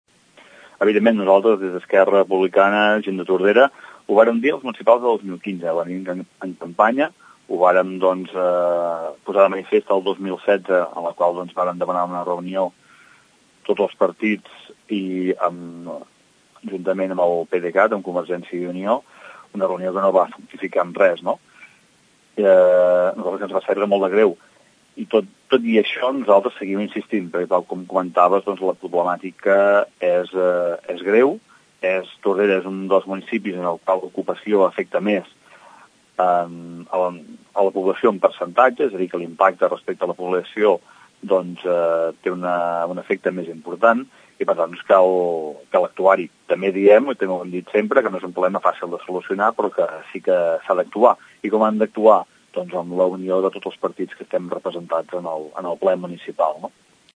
El grup municipal republicà apunta que la  problemàtica segueix essent greu, i reclama la necessitat d’intervenir d’una forma més decidida. Així ho explica el regidor Xavier Pla.